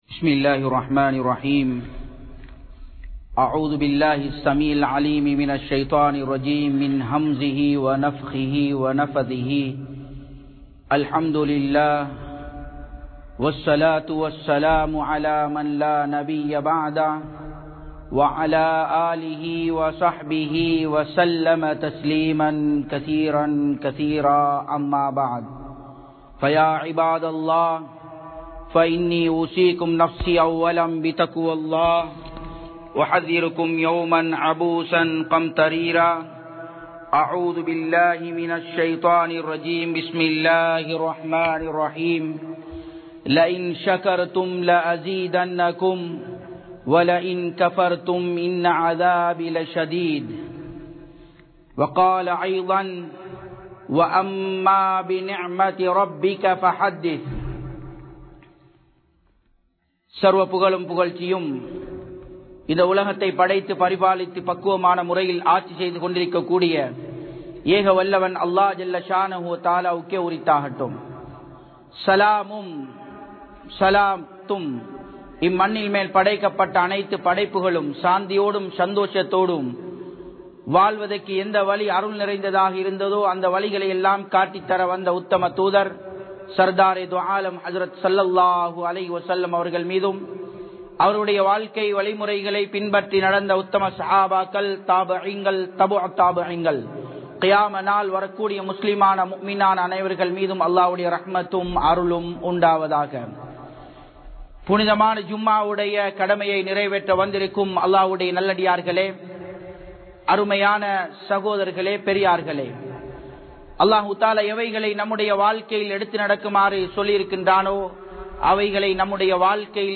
Allahvin Arutkodaihalukku Nantri Seluthungal (அல்லாஹ்வின் அருட்கொடைகளுக்கு நன்றி செலுத்துங்கள்) | Audio Bayans | All Ceylon Muslim Youth Community | Addalaichenai